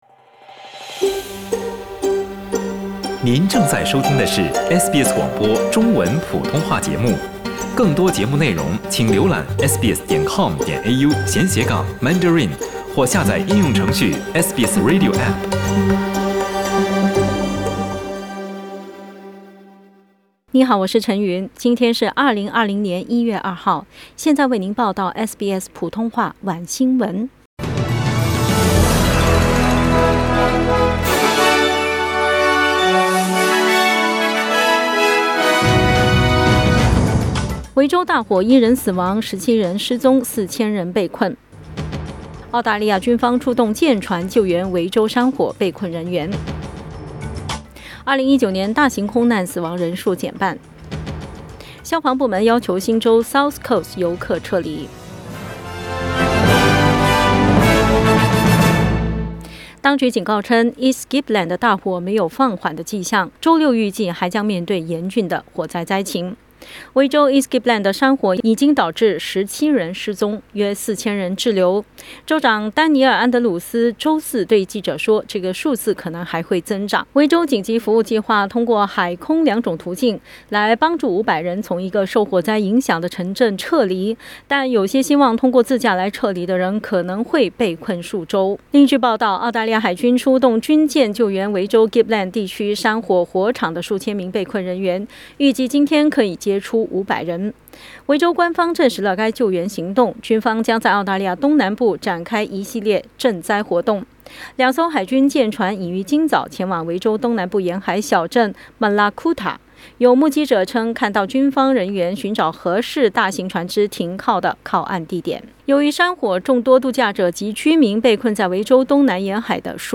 SBS晚新闻（2020年1月2日）